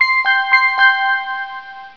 可動式ホーム柵が開く際の音